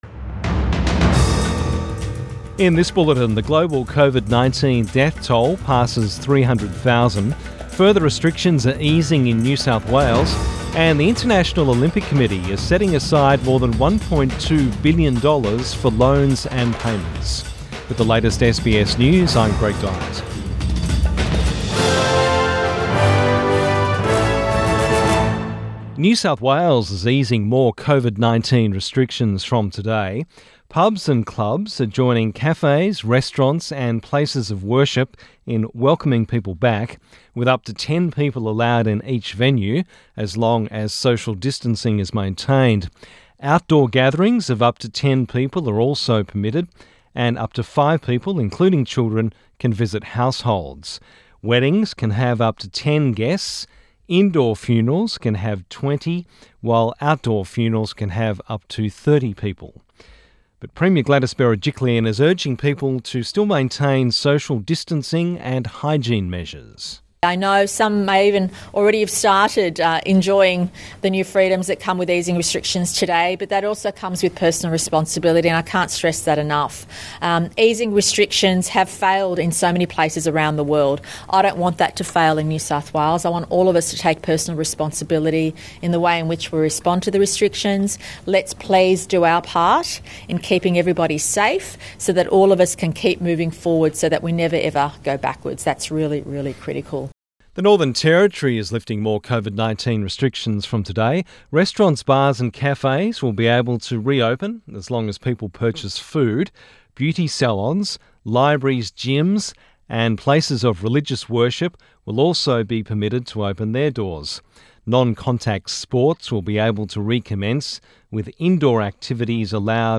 Midday bulletin 15 May 2020